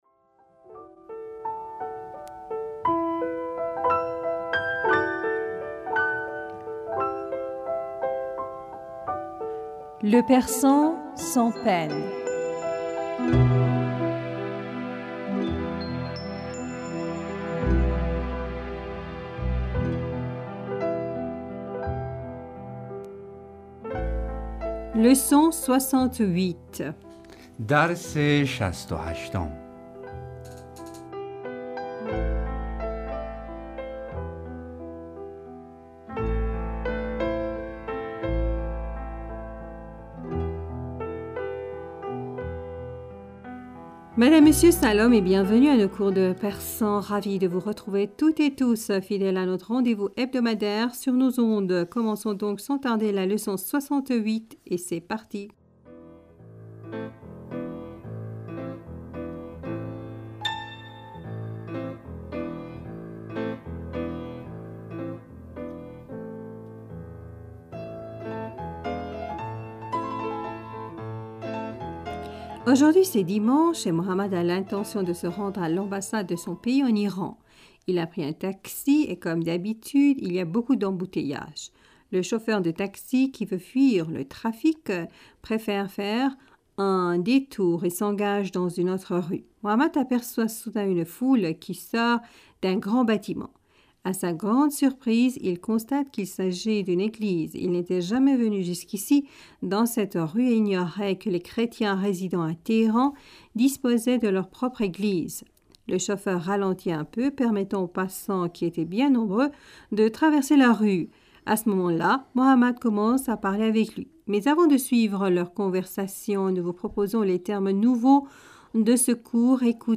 Ecoutez et répétez après nous.